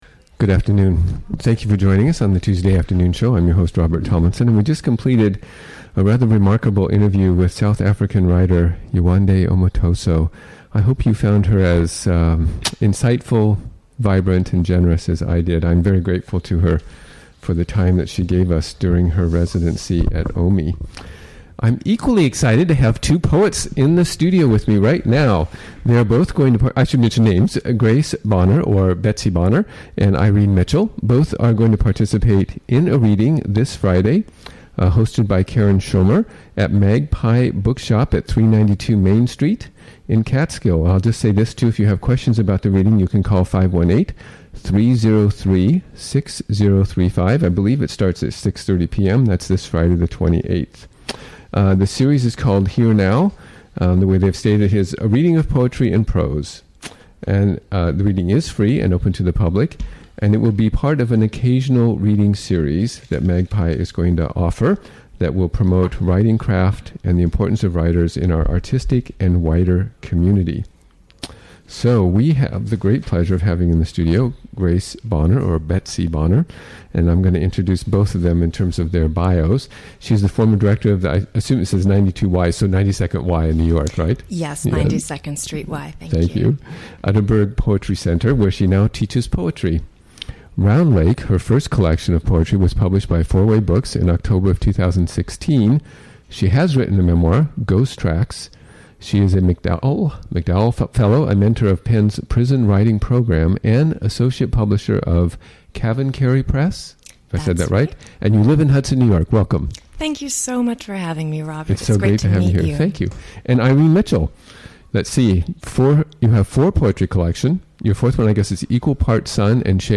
Recorded during the WGXC Afternoon Show of Tuesday, July 25, 2017.